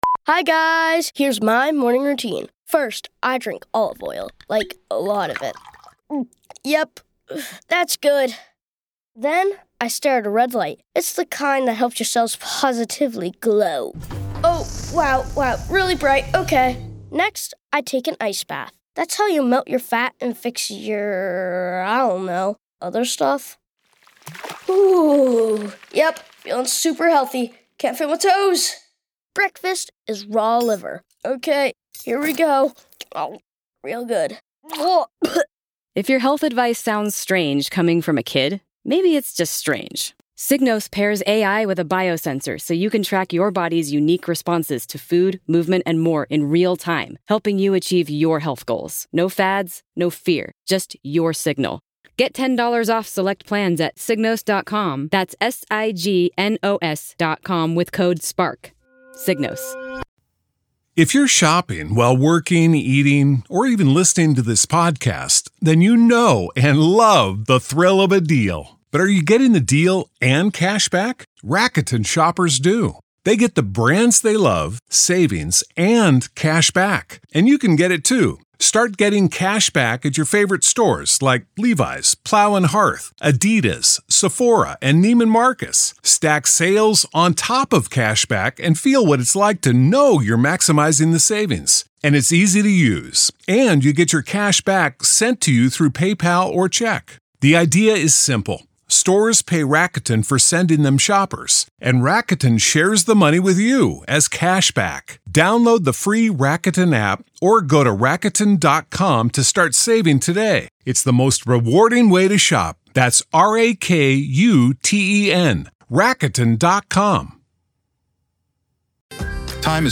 Far from sensational, this conversation is emotionally grounded and painfully honest.